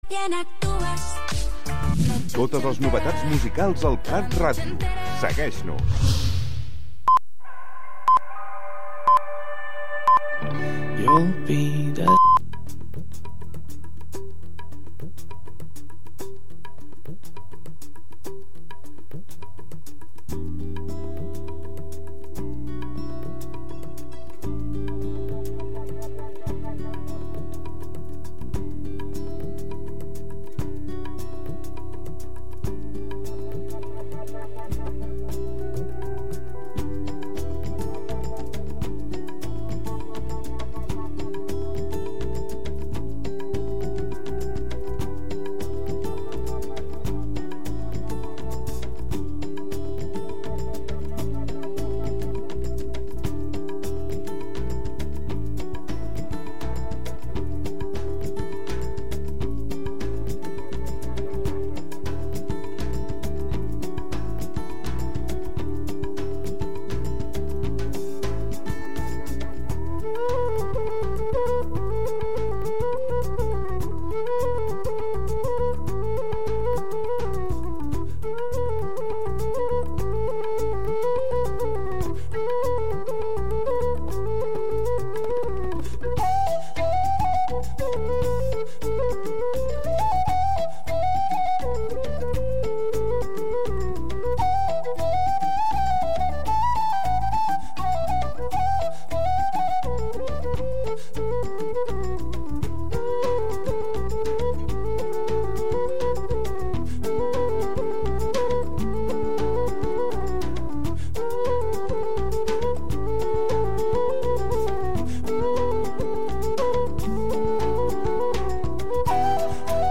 Recorreguts musicals pels racons del planeta, música amb arrels i de fusió. Sense prejudicis i amb eclecticisme.